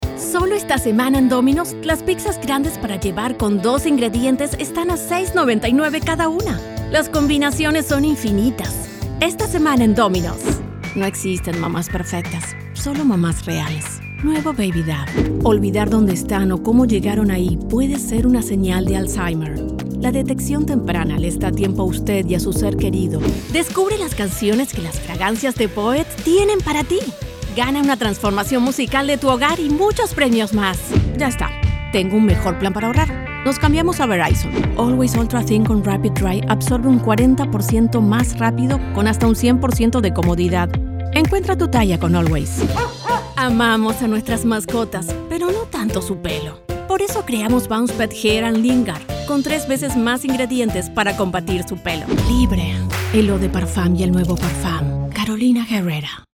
Cálida, Profundo, Natural, Versátil, Empresarial, Joven, Cool, Accesible, Amable, Suave
Comercial
Her voice has been described as warm, friendly, casual, smooth and sensual, depending on the projects.